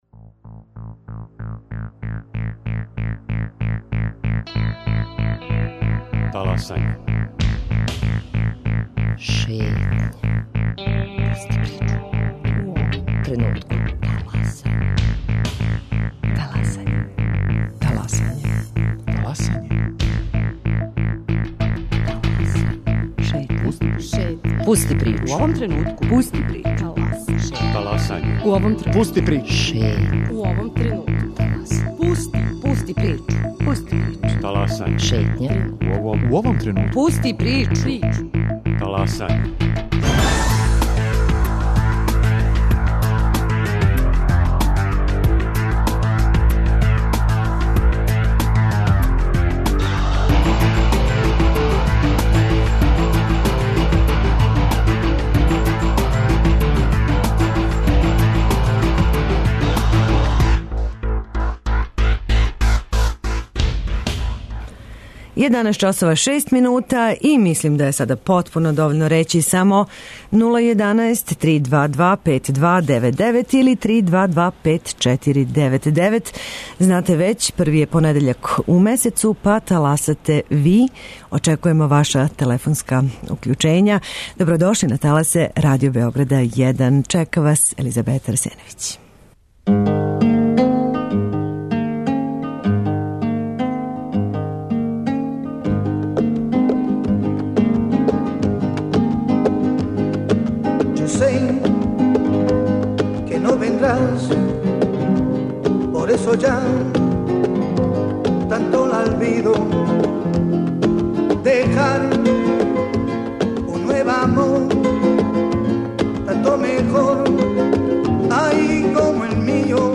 Само - контакт програм Радио Београда 1! И последњег овогодишњег првог понедељка у месецу, таласате - Ви!